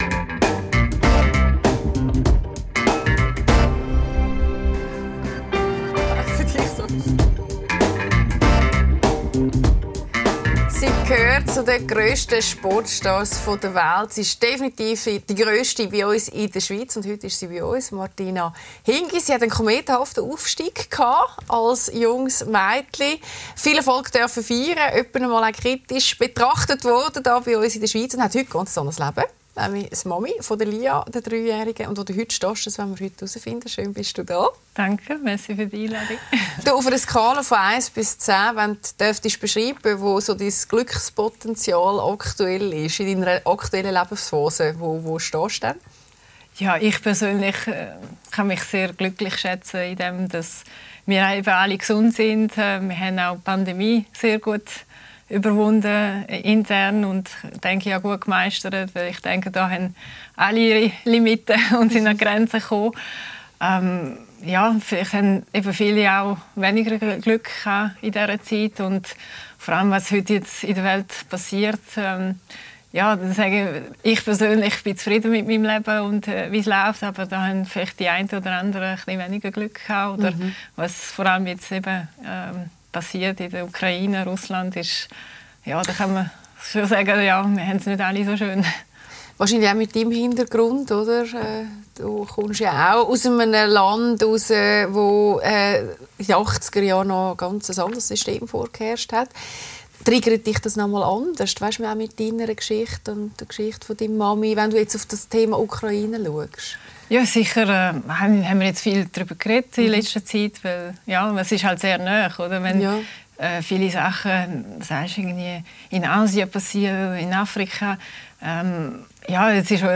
Lässer Classics zeigt die besten Talks aus den letzten zehn Jahren mit Claudia Lässer. In dieser Folge: Martina Hingis.